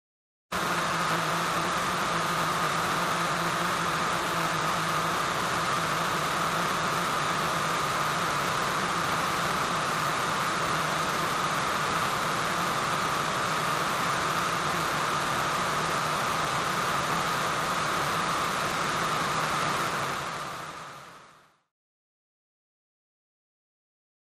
Distortion Distorted Drone with Low End Buzz, Static